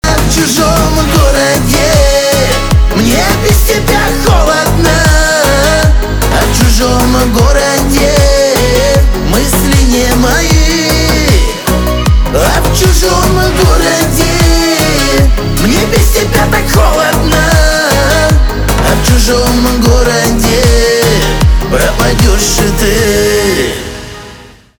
шансон
чувственные